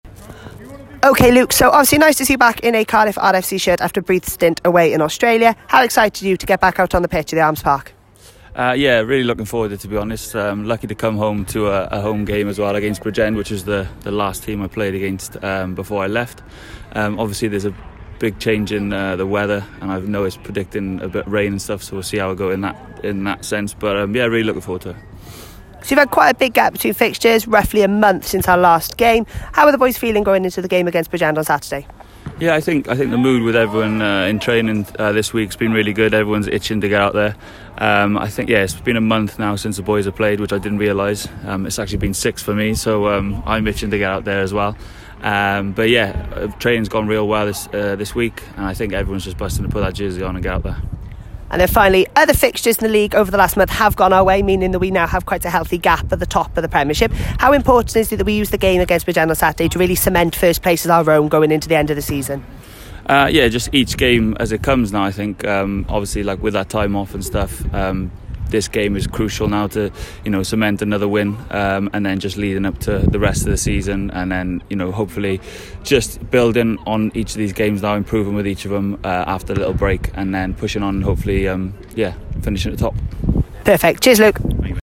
Pre-match Interview.